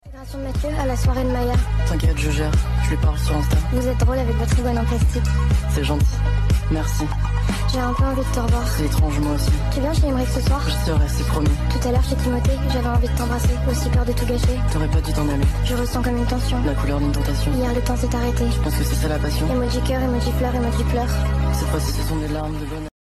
sms sound effects free download